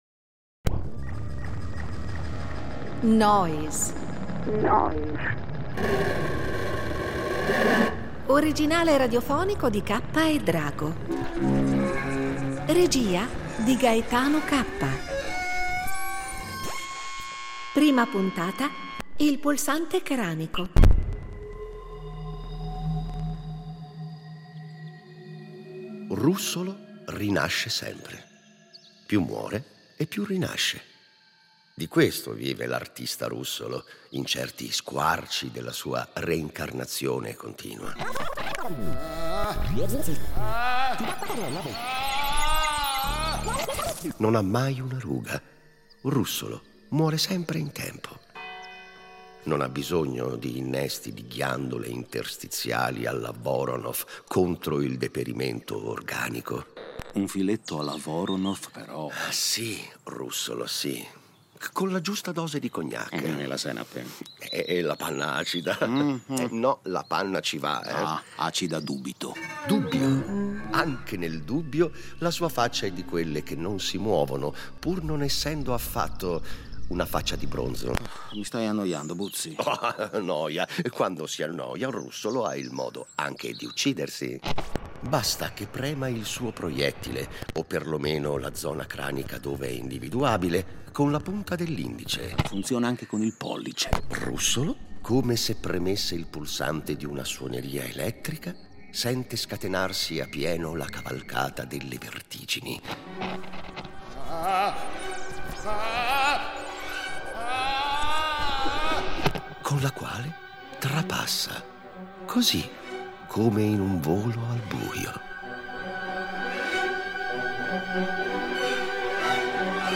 Nel radiodramma abbiamo però privilegiato il Russolo musicista, compositore e inventore di strumenti sonori.